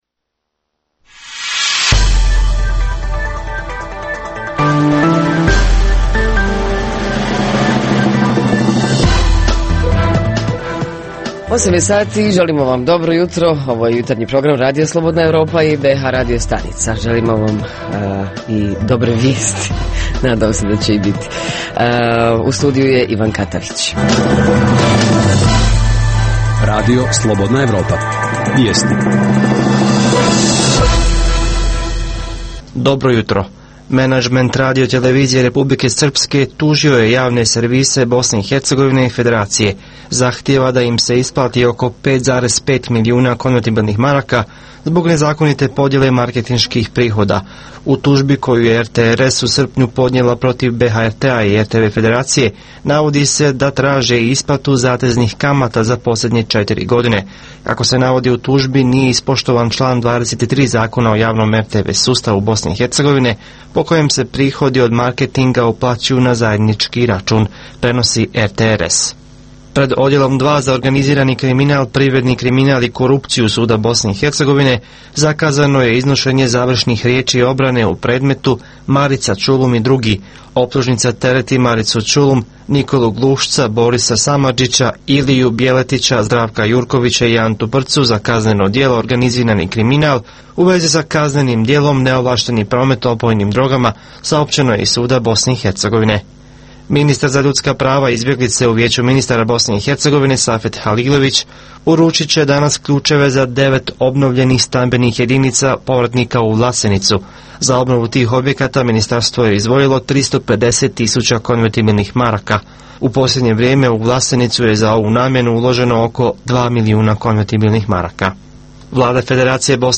U Jutarnjem programu tražimo odgovor na pitanje kako se zabavljaju mladi tokom ljeta. Reporteri iz cijele BiH javljaju o najaktuelnijim događajima u njihovim sredinama.
Redovni sadržaji jutarnjeg programa za BiH su i vijesti i muzika.